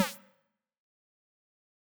shoot_3.wav